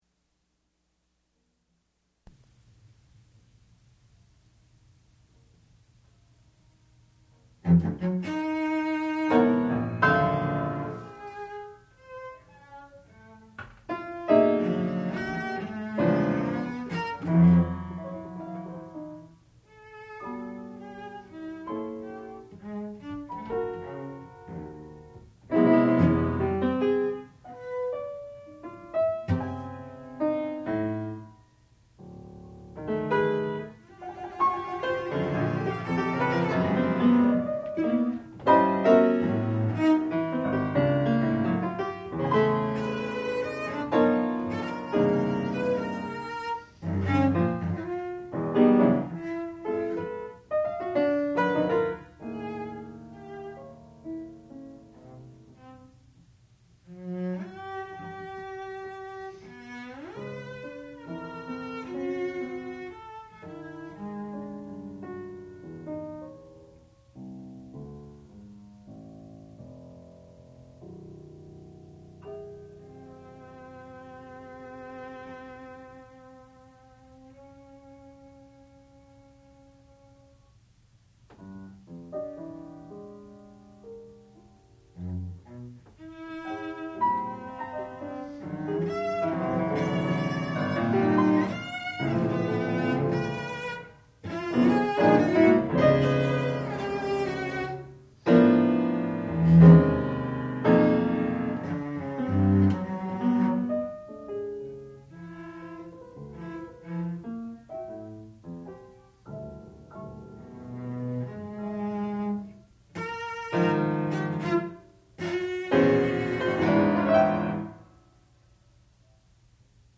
sound recording-musical
classical music
violoncello
Cello